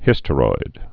(hĭstə-roid)